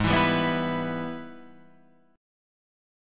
G#mM7#5 chord